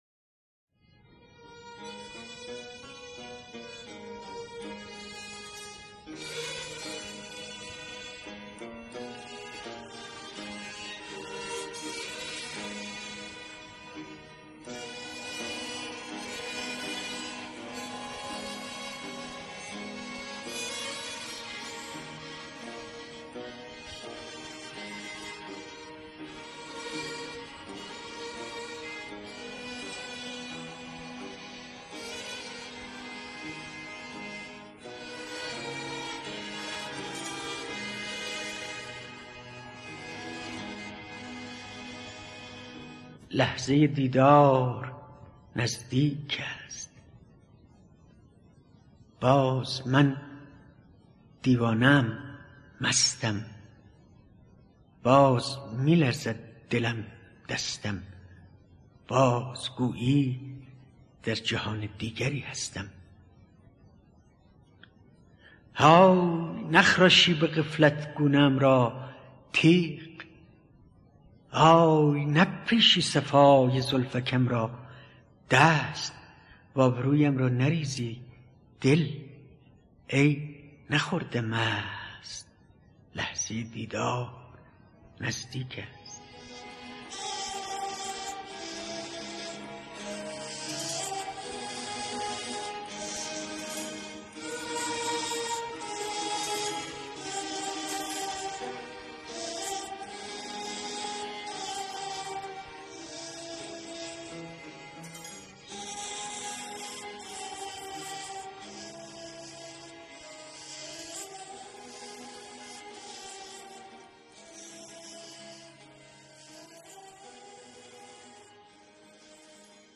فایل صوتی دکلمه شعر لحظه دیدار با صدای مهدی اخوان ثالث